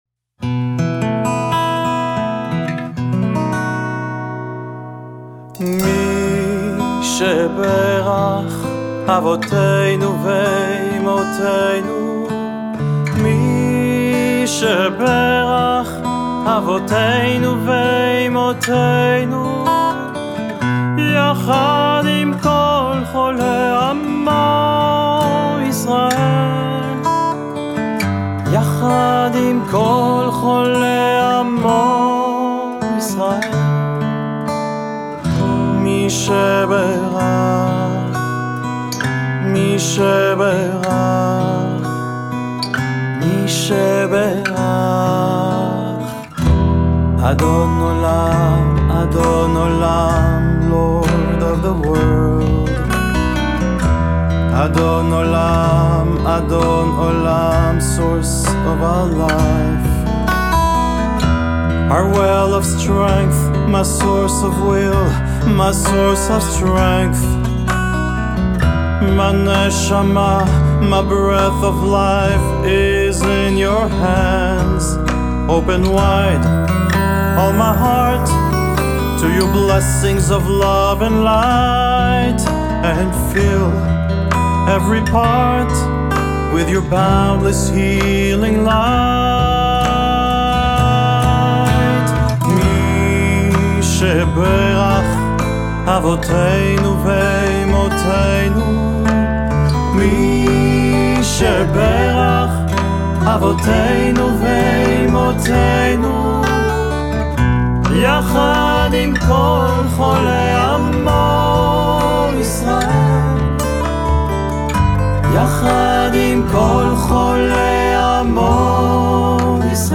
An Original Mishkan Miami Meditative song and prayer, drawn from our Jewish traditions, to give you hope, comfort, strength and peace (click here to listen and download):
Meditative_Songs.mp3